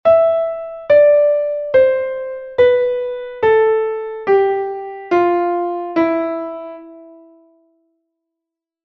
Dórico
mi-re-do-si-la-sol-fa-mi